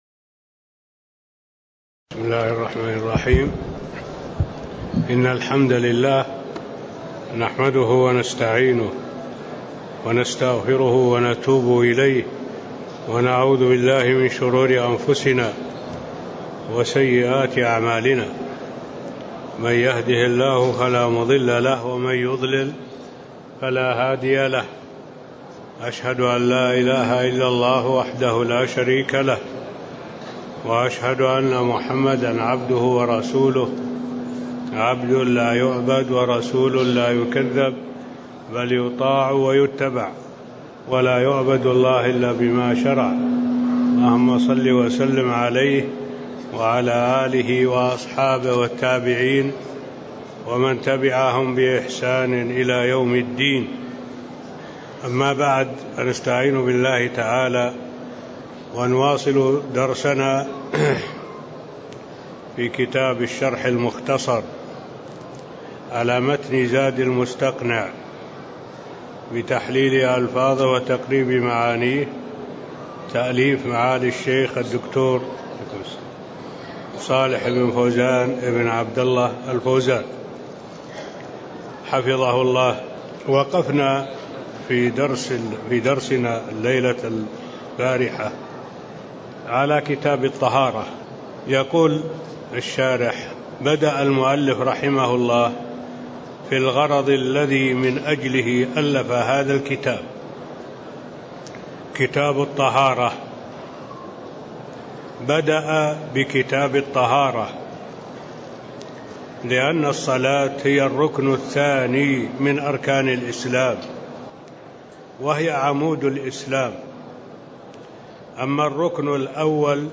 تاريخ النشر ١٤ ربيع الأول ١٤٣٤ هـ المكان: المسجد النبوي الشيخ: معالي الشيخ الدكتور صالح بن عبد الله العبود معالي الشيخ الدكتور صالح بن عبد الله العبود المقدمة (01)